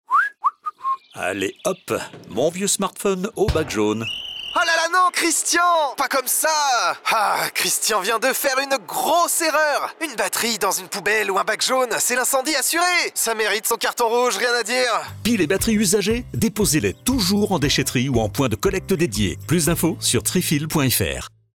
Télécharger le spot radio : Piles et batteries : 100% déchèterie